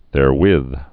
(thâr-wĭth, -wĭth)